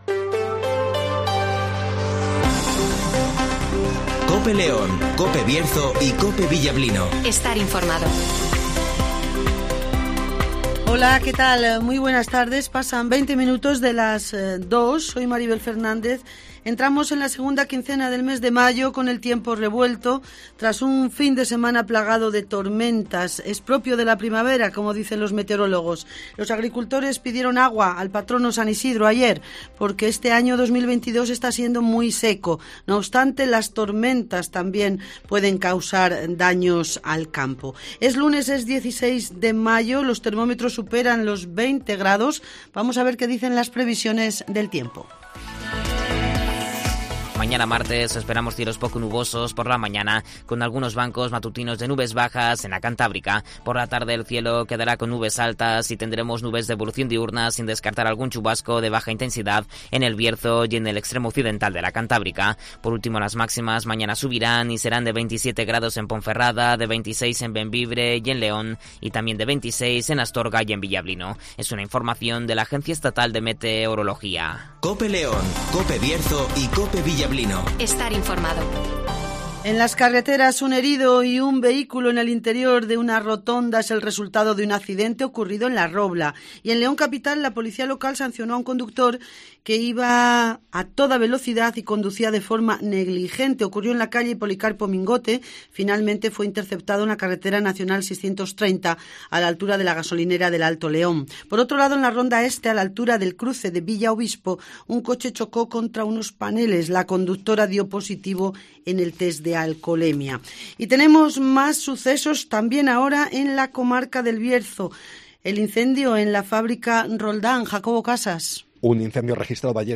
- Eduardo Morán ( Presidente de la Diputación )
- José Antonio Díez ( Alcalde de León )